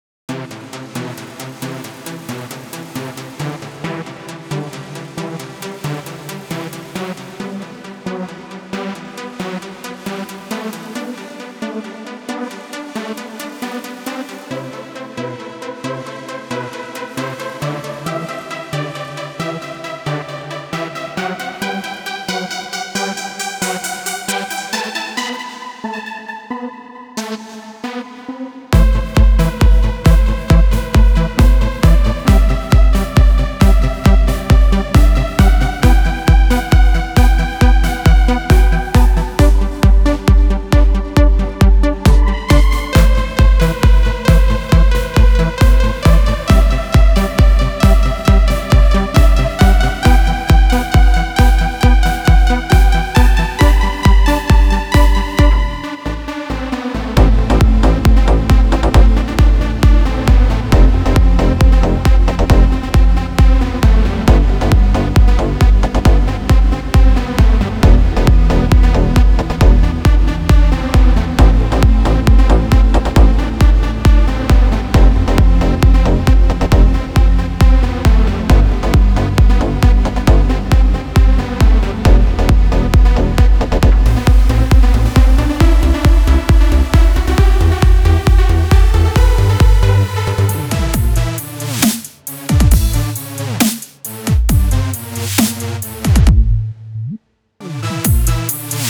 הקצב במנגינה שעשיתי לא תואמים